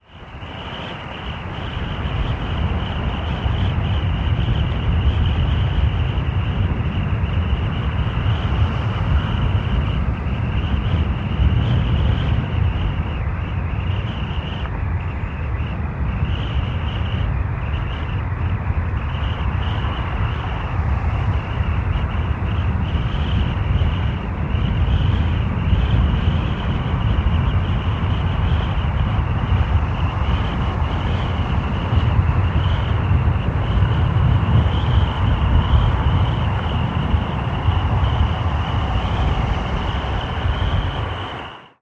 Гул ураганного ветра при цунами